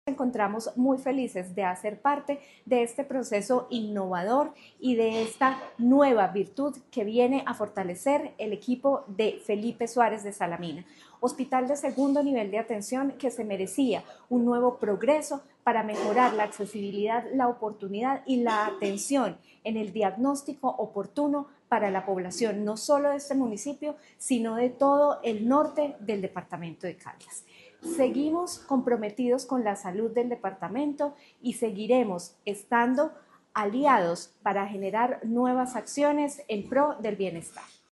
Natalia Castaño Díaz, Directora de la DTSC.
AUDIO-NATALIA-CASTANO-DIAZ-DIRECTORA-DTSC-ENTREGA-TOMOGRAFO-SALAMINA.mp3-online-audio-converter.com_.mp3